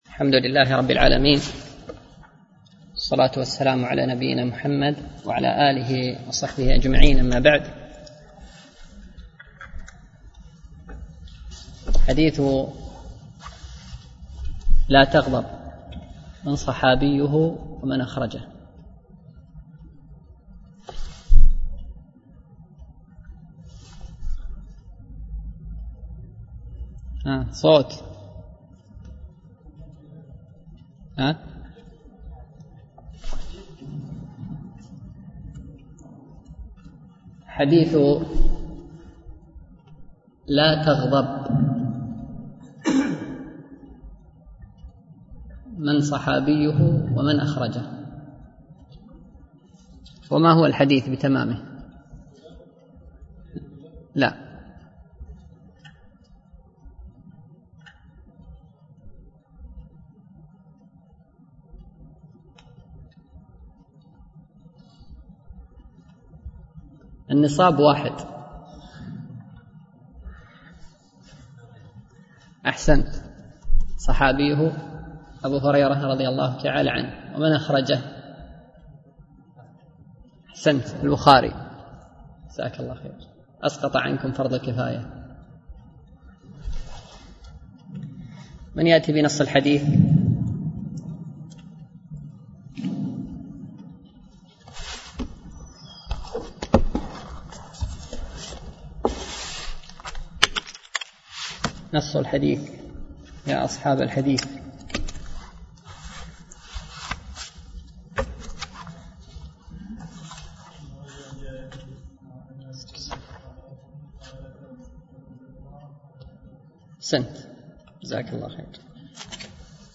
شرح عمدة الأحكام ـ الدرس الرابع والعشرون